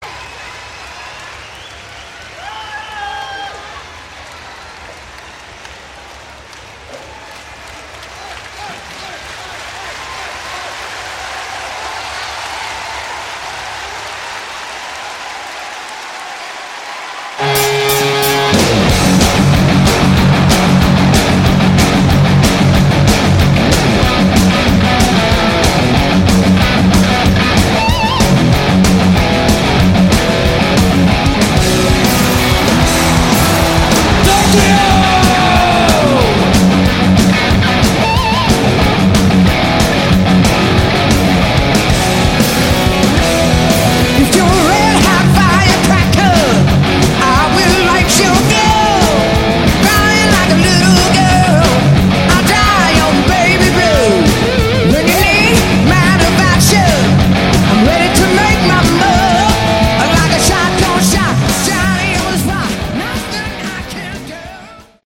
Category: Hard Rock
Bass
vocals
Drums
Guitars